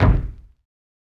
Kick.wav